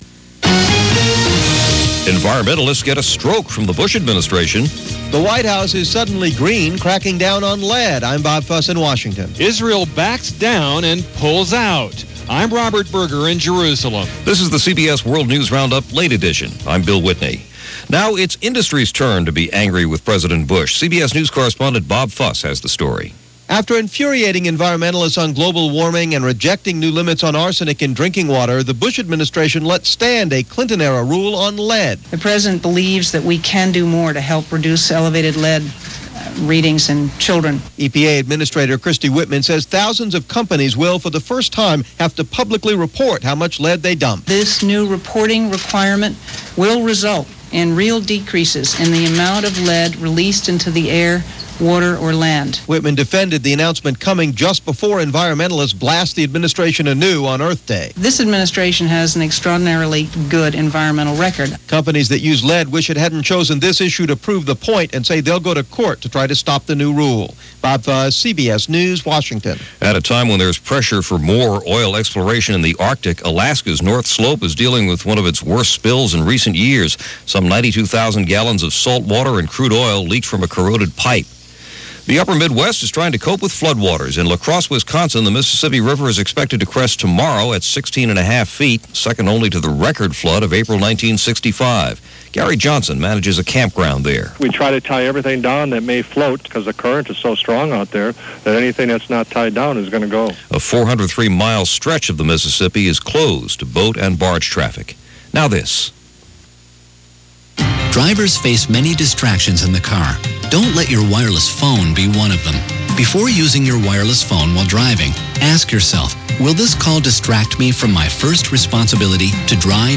That’s a slice of what went on, this April 17th in 2001 as presented by The CBS World News Roundup.